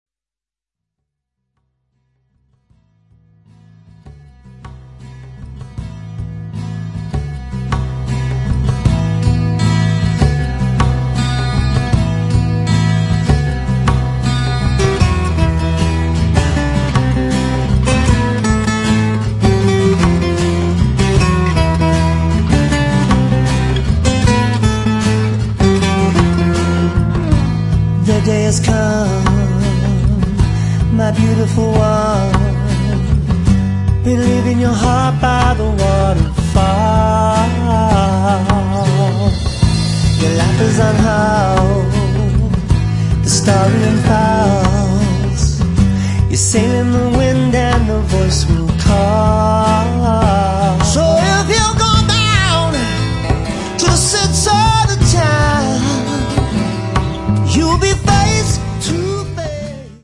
Рок
Двойной концертник рок-легенды.